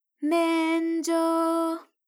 ALYS-DB-002-JPN - Source files of ALYS’ first publicly available Japanese vocal library, initially made for Alter/Ego.